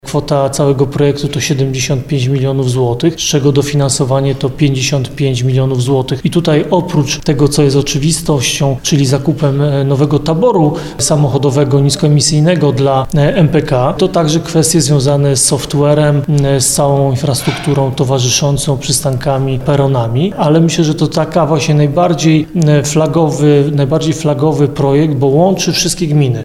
Prezydent Nowego Sącza i prezes stowarzyszenia Ludomir Handzel przekazał nam, że największa kwota zostanie przeznaczona na usprawnienie transportu na Sądecczyźnie, a konkretnie chodzi o projekt spółki MPK.